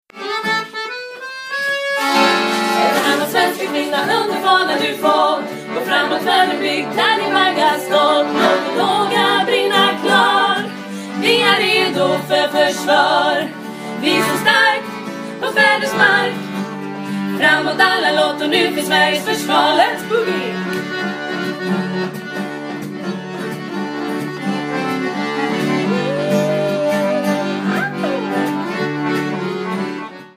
Då var dagens musikmöte avklarat. Och hej vilka  instrumentalister vi skrapat ihop!